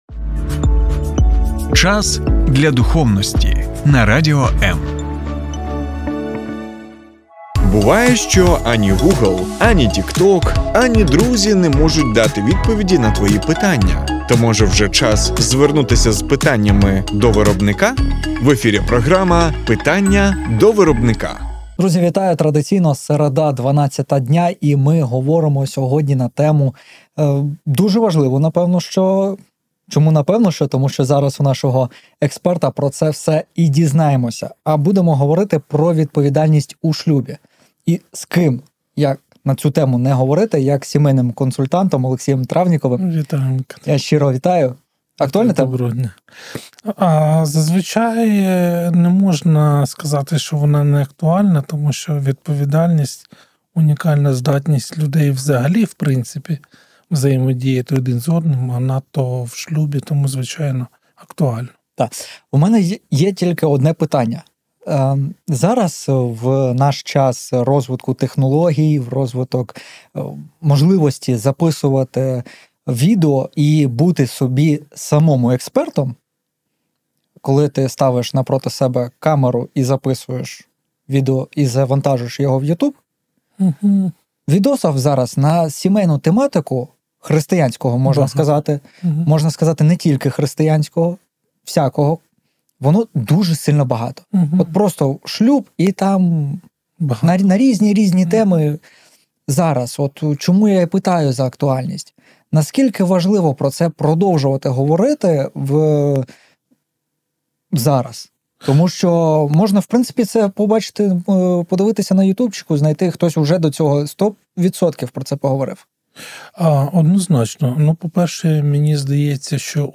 Завантажати запис ефіру на тему: Що таке відповідальність у шлюбі? Точно не те, що ти думав